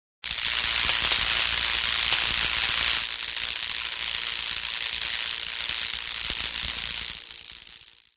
Звуки шаровой молнии
Подборка включает разные варианты звучания, от глухих разрядов до шипящих импульсов.
Звук проявление шаровой молнии мгновенное